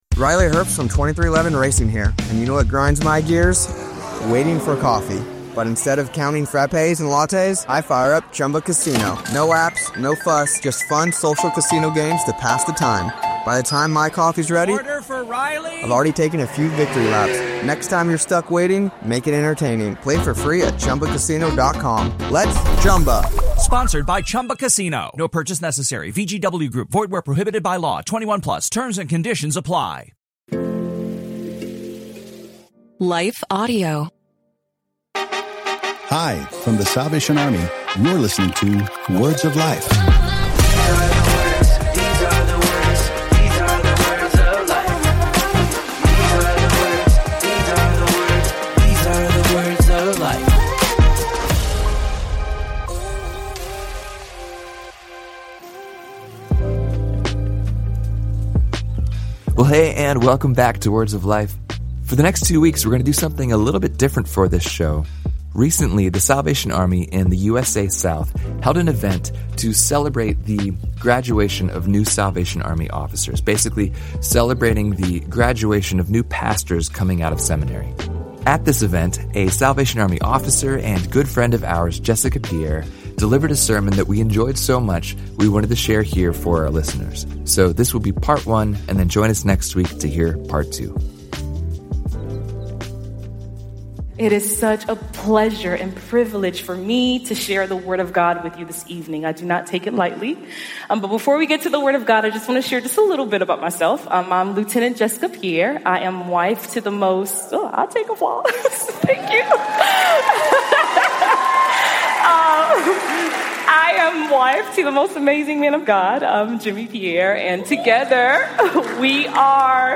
This sermon was delivered at a recent Salvation Army event in Atlanta GA.